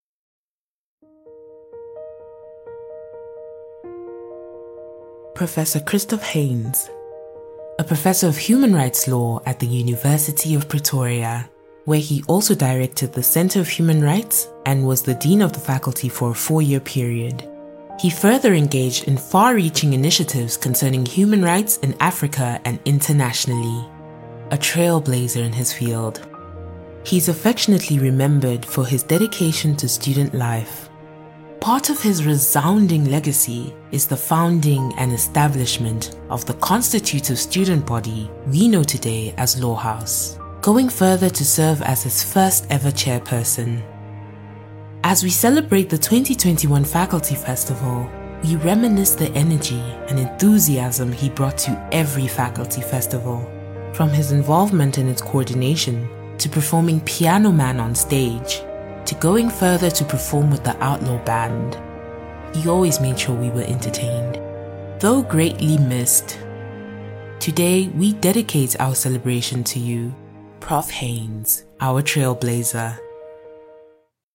Vocal Styles:
caring, compassionate, maternal, nurturing
My demo reels